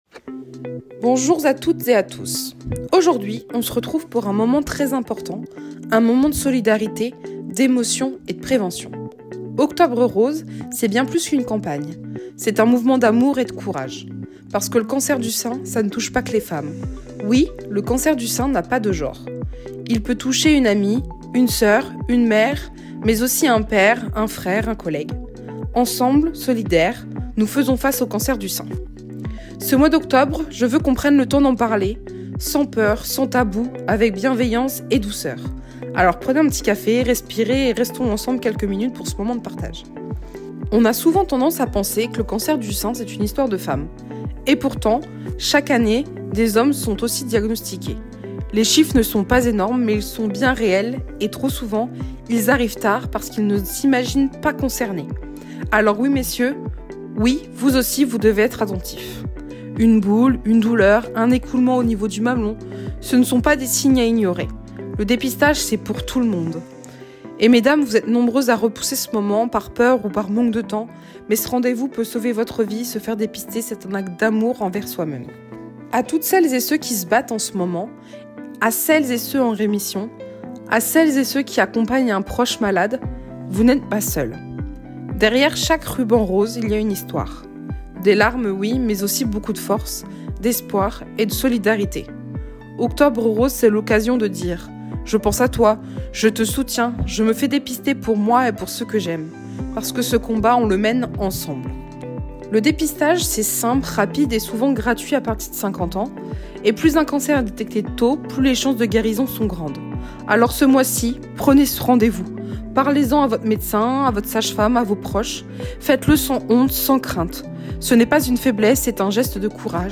À travers des témoignages, des informations pratiques et des messages de sensibilisation, cette chronique rappelle l’importance du dépistage précoce et du soutien à la recherche.